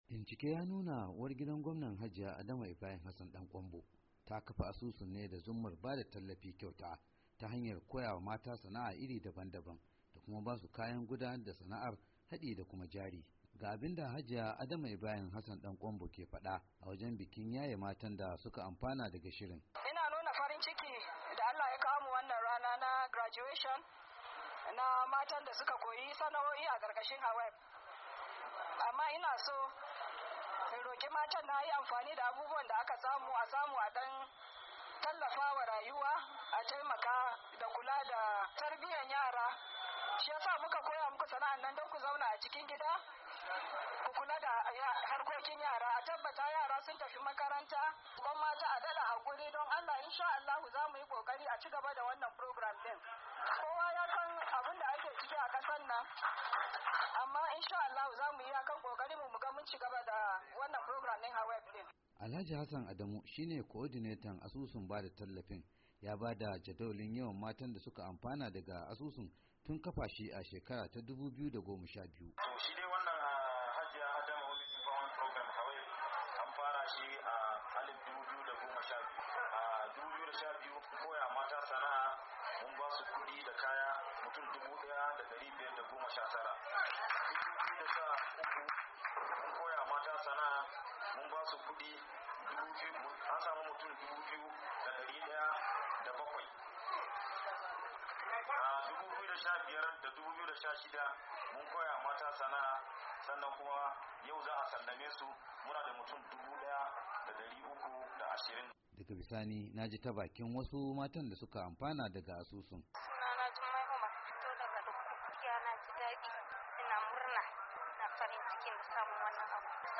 WASHINGTON DC —